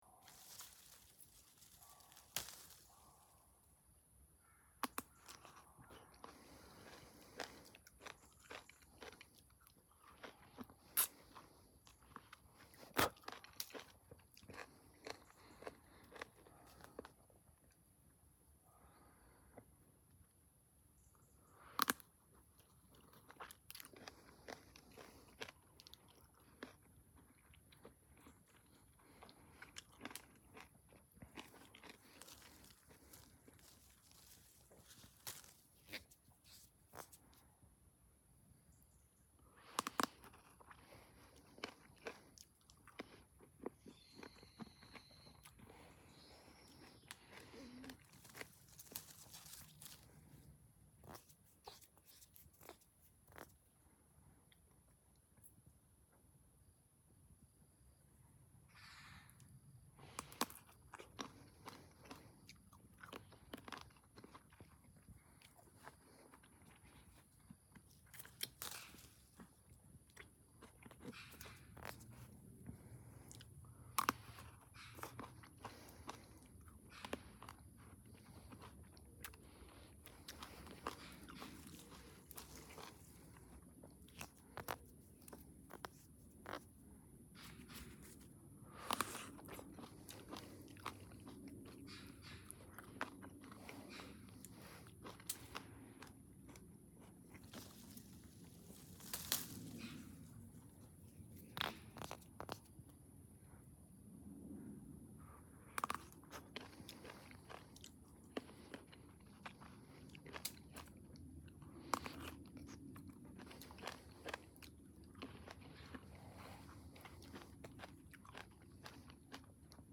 Шум, издаваемый людьми во время кушанья еды, разные варианты
15. Человек срывает алычу с дерева и ест, звук хруста и жевания плода